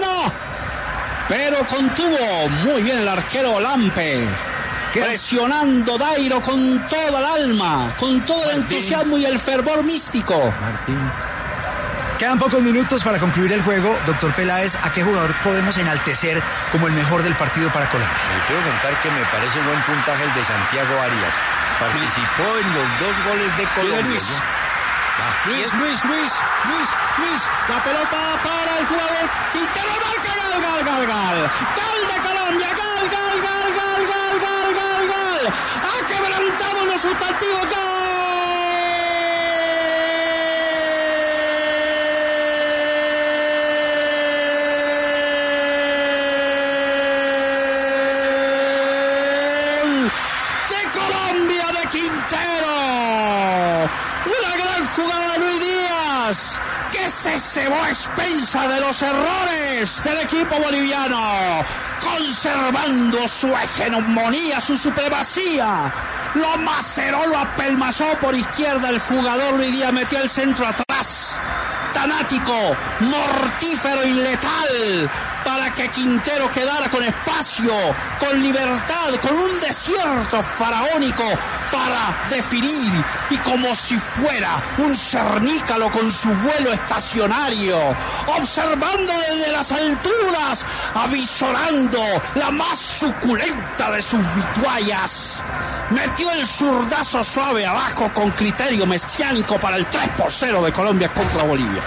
¡Mortífero y letal!: Así fue la narración de Martín de Francisco al gol de ‘Juan Fer’ Quintero
Martín De Francisco narró con todo el sentimiento el gol de Quintero ante Bolivia.